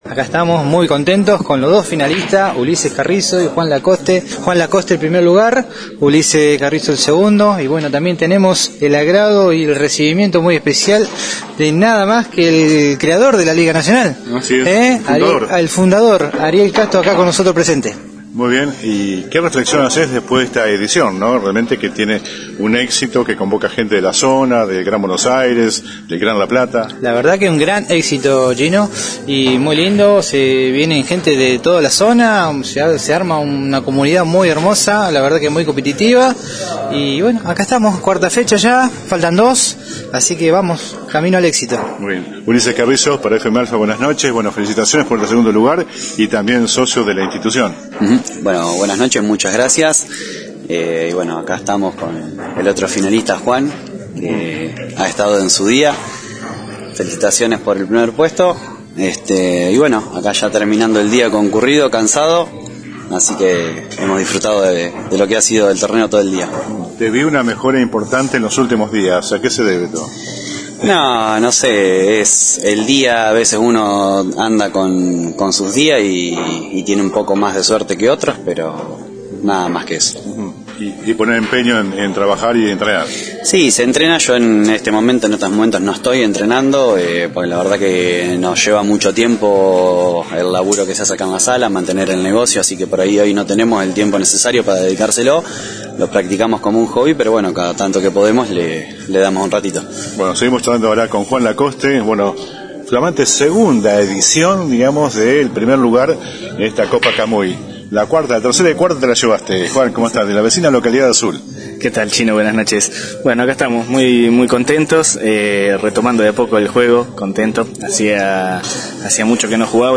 La charla con los protagonistas: